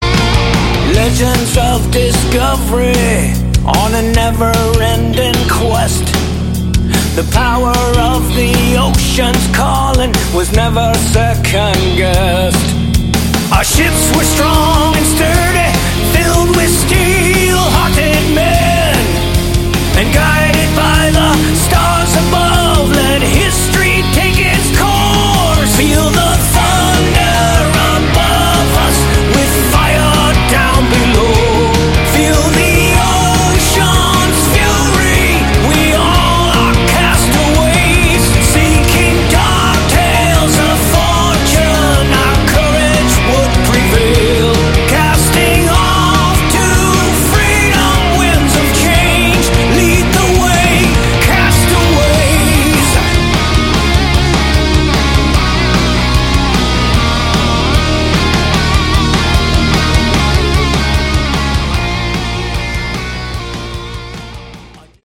Category: Melodic Rock
bass
vocals
guitars
drums
Catchy choruses, Good Melodic HardRock. 80/100